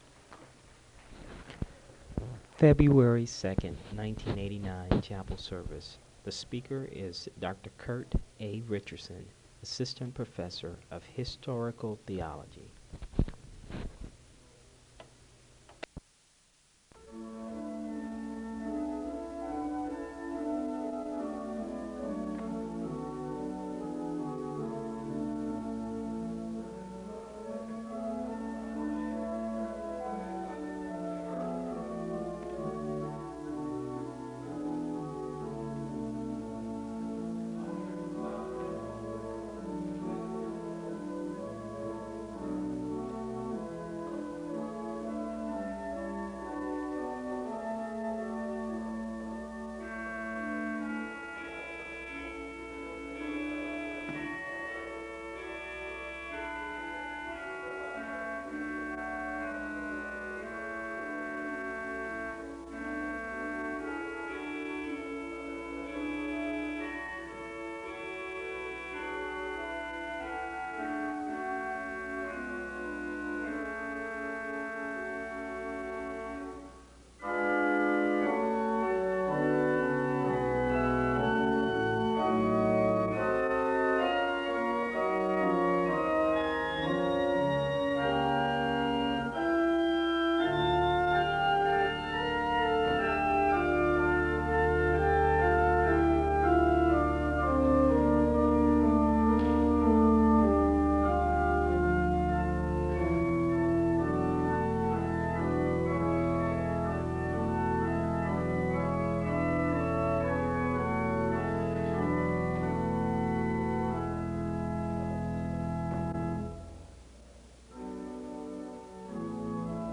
A hymn is played (0:15-3:17).
The congregation sings a hymn (cut) (3:18-3:35). The Scripture reading is Acts 1:6-11 (3:36-4:37). A word of prayer is given (4:38-5:50). The choir sings a hymn (5:51-9:57).
A hymn closes the service (23:38-28:26).